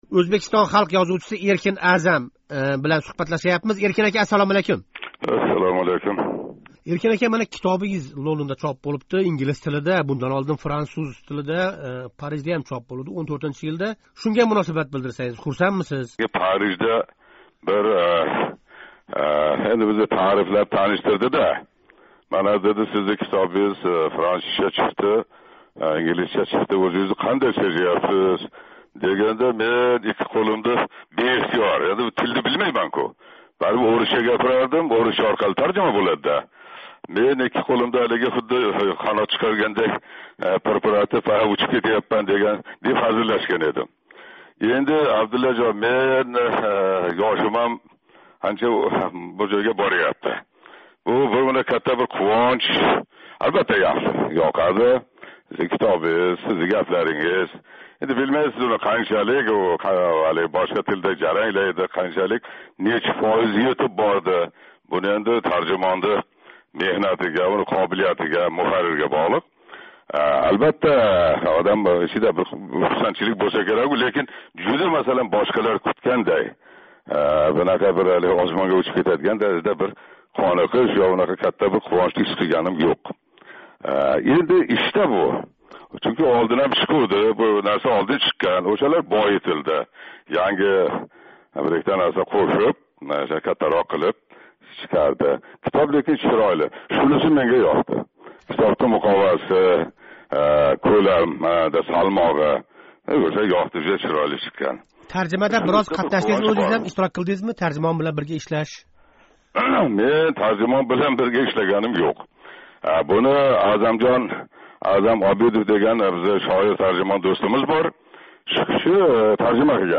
Эркин Аъзам билан суҳбат